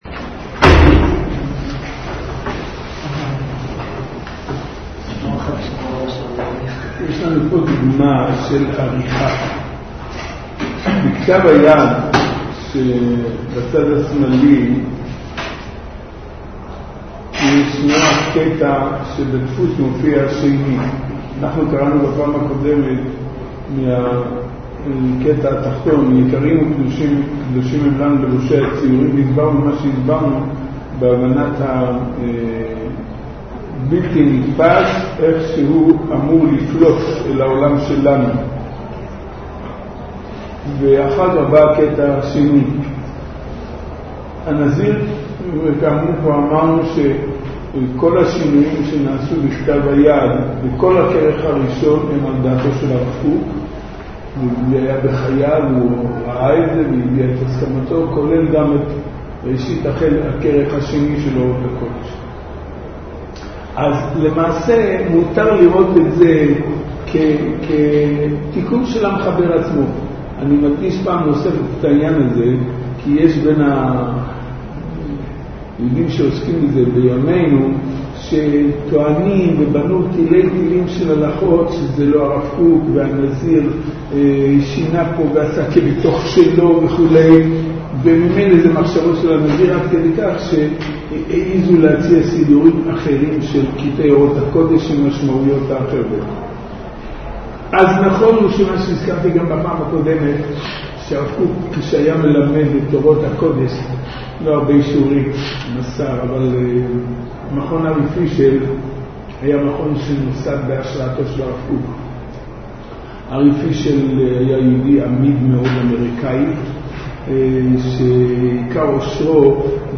תשס"ז להאזנה לשיעור: https